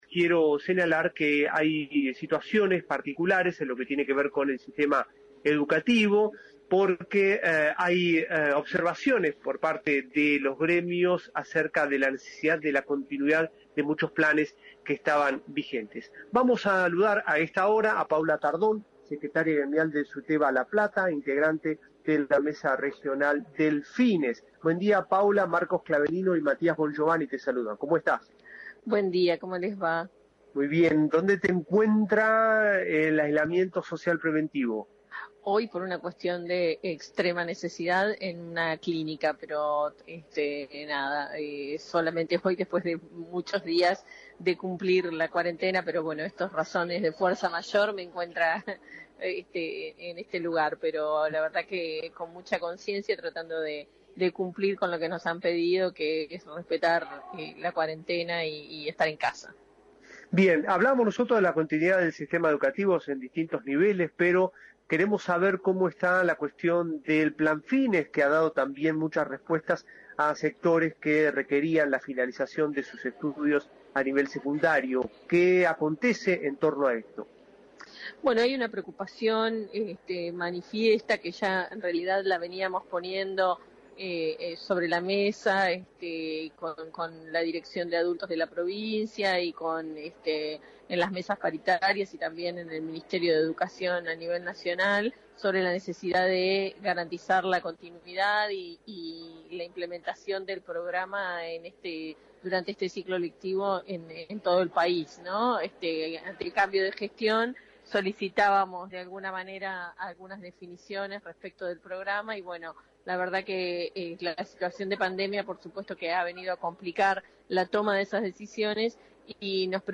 Continuidad del Plan Fines: Entrevista